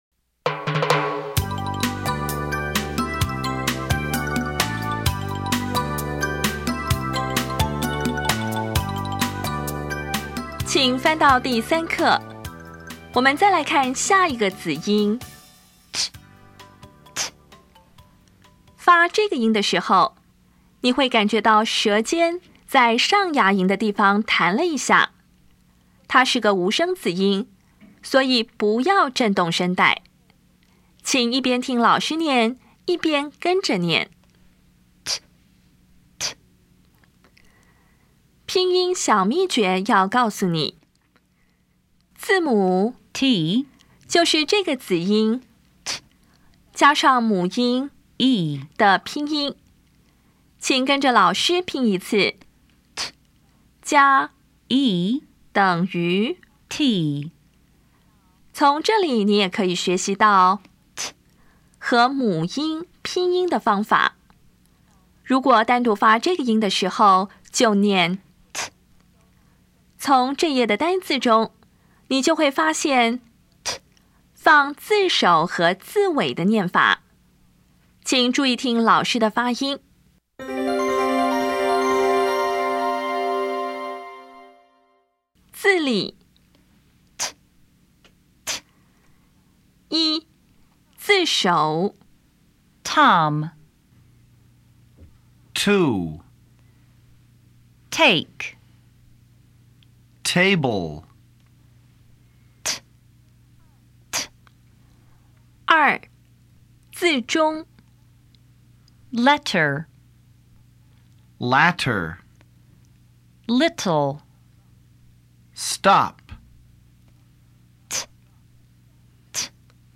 当前位置：Home 英语教材 KK 音标发音 子音部分-1: 无声子音 [t]
音标讲解第三课
[ˋteb!]
[ˋlɛtɚ]